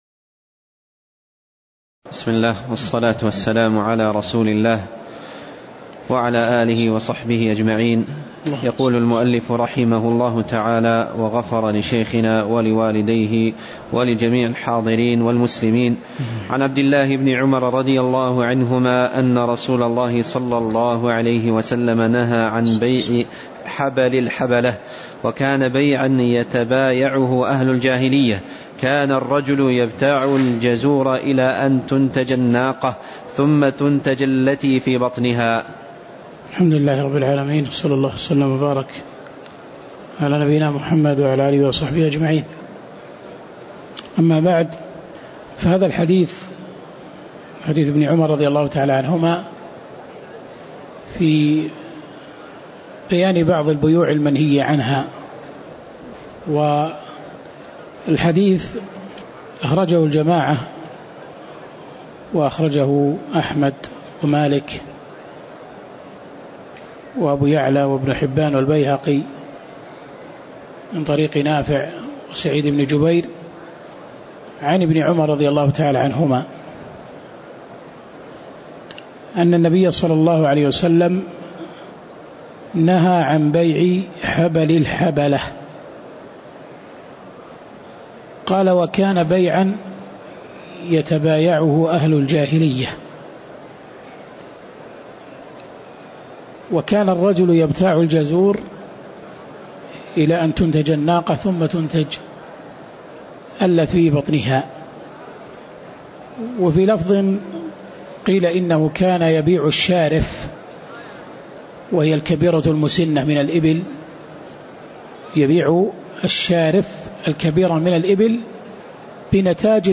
تاريخ النشر ٢٦ رجب ١٤٣٨ هـ المكان: المسجد النبوي الشيخ